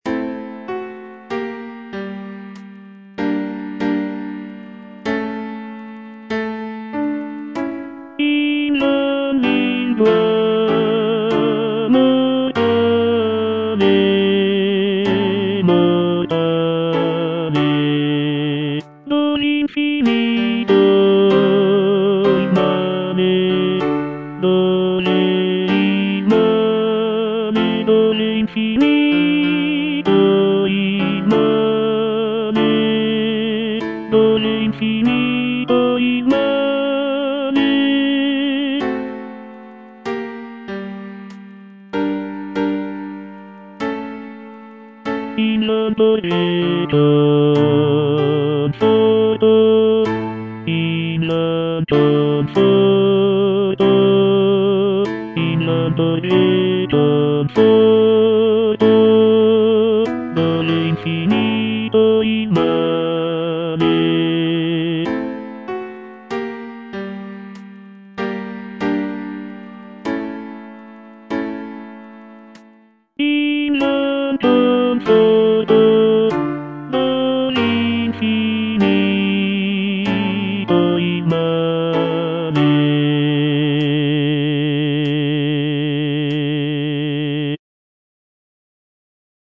C. MONTEVERDI - LAMENTO D'ARIANNA (VERSION 2) Coro I: Invan lingua mortale - Tenor (Voice with metronome) Ads stop: auto-stop
The piece is based on the character of Ariadne from Greek mythology, who is abandoned by her lover Theseus on the island of Naxos. The music is characterized by its expressive melodies and poignant harmonies, making it a powerful and moving example of early Baroque vocal music.